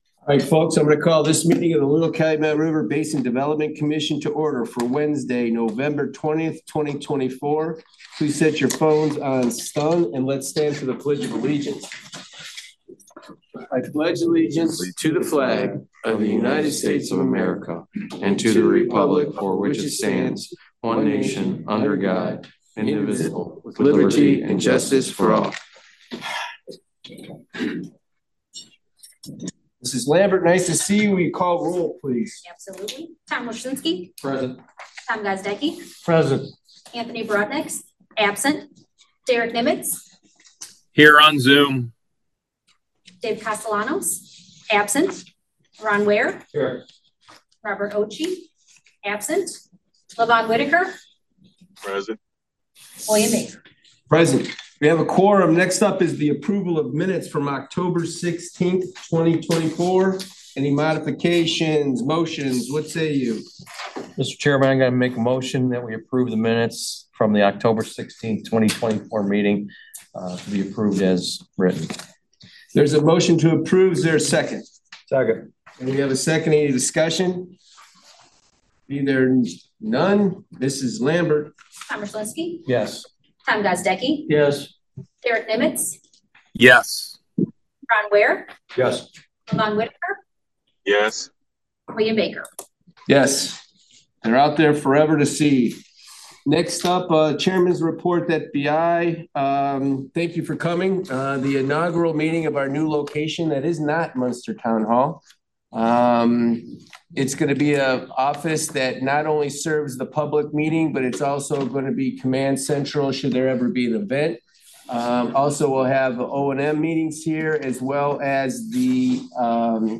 11/20/24 Public Meeting